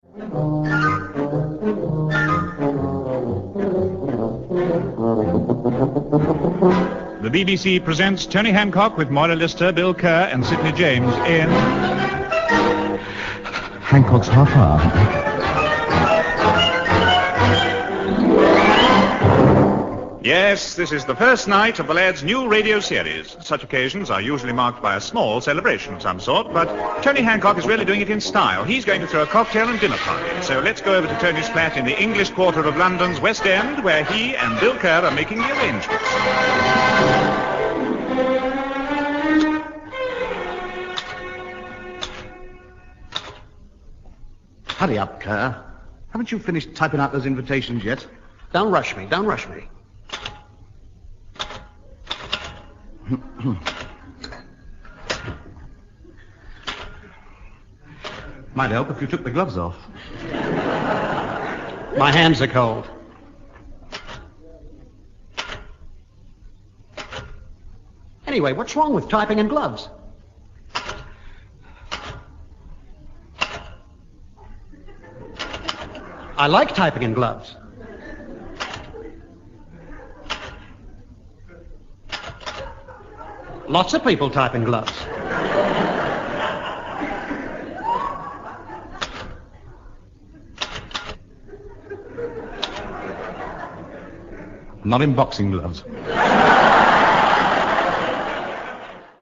To this day, Birmingham-born Tony Hancock is remembered for his contribution to entertainment and to distinctive, brilliantly-timed, deadpan radio comedy.
Tony starred alongside: Sid James; Hattie Jacques; and Kenneth Williams, amongst others. Tony played a character with similarities to his inner self: a down-at-heel comedian living, as the series went on, at 23 Railway Cuttings, East Cheam. The first series launched on the BBC Light Programme on the 2nd of November 1954, from which episode this clip is taken.